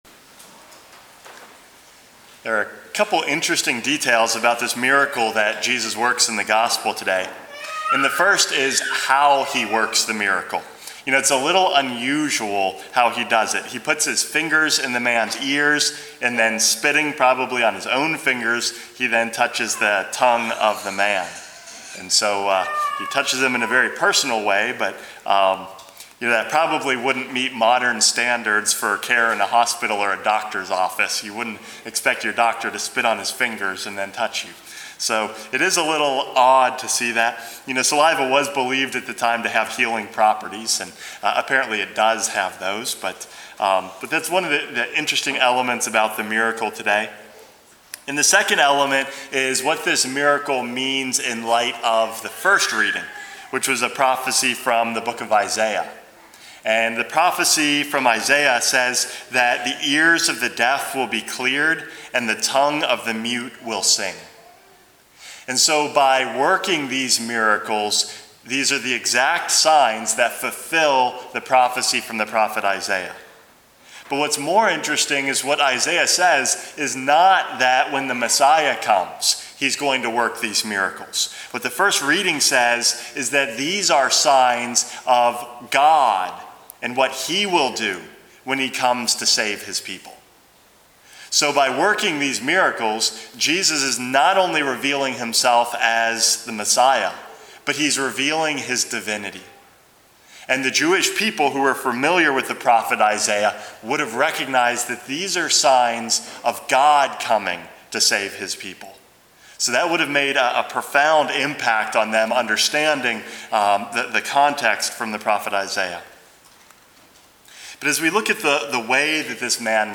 Homily #413 - From the Hand of God